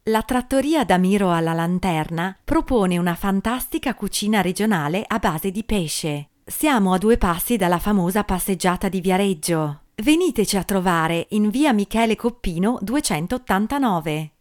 Female; 20s/30s, teenager - sunny versatile voice over - modern, fresh and lively, sexy and mellow, native Italian speaker. Standard Italian accent.
Sprecherin italienisch, Muttersprachlerin.
Sprechprobe: Werbung (Muttersprache):